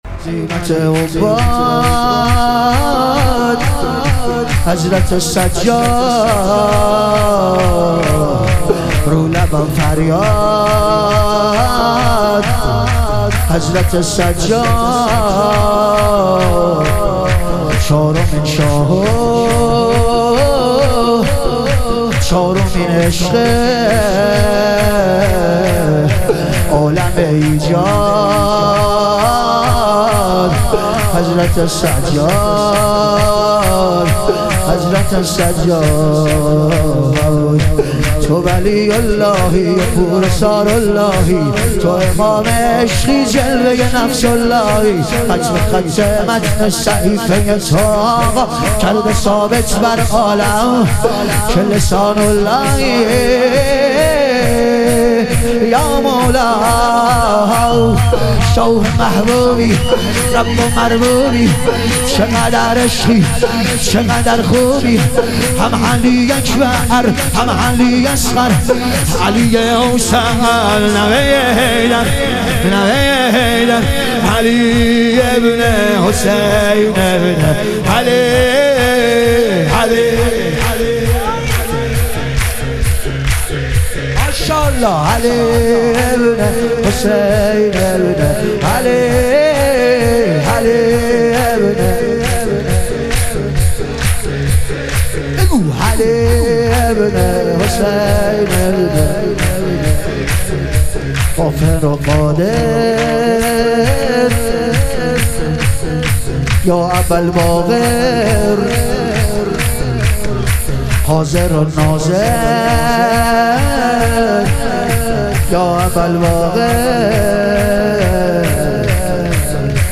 ظهور وجود مقدس امام سجاد علیه السلام - شور